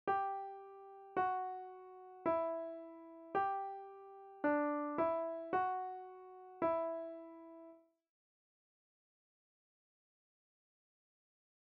This tune is wonderfully "modal" - that is, neither major nor minor.
Notice that the pitch on "that" in the first line is the same pitch as "Christ", and then drops a perfect fourth.
MIDI recording of the notes at the trouble spot, then of the
Thanksgiving_Hymn_A_trouble_spot.mp3